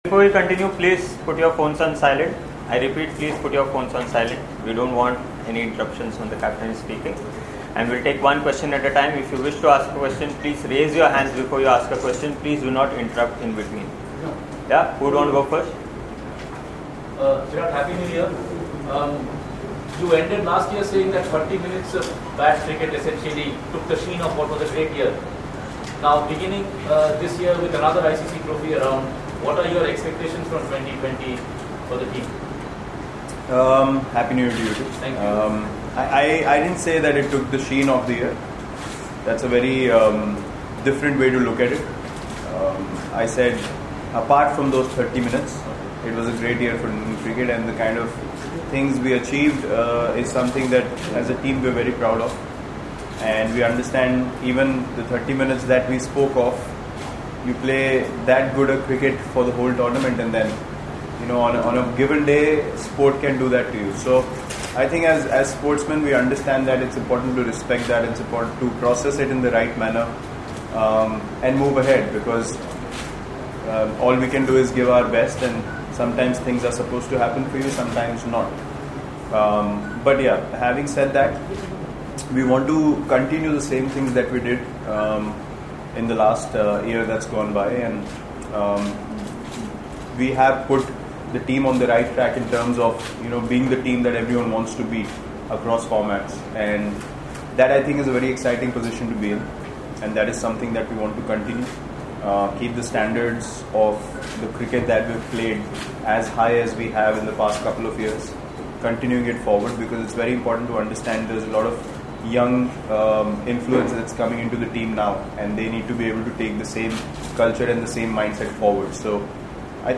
Virat Kohli spoke to the media in Guwahati on Saturday ahead of the 1st T20I against Sri Lanka.